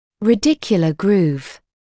[ræ’dɪkjələ gruːv][рэ’дикйэлэ груːв]корневой желобок